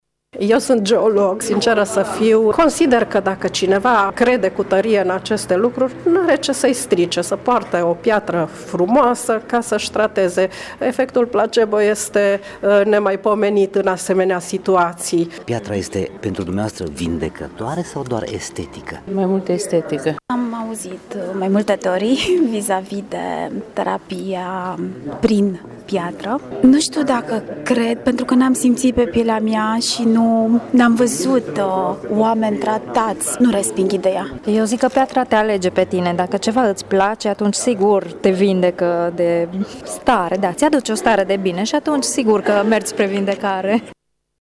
Opiniile vizitatorilor sunt împărţite. Unii nu cred în virtuţile terapeutice ale cristalelor, altii sunt atrași de partea lor estetică: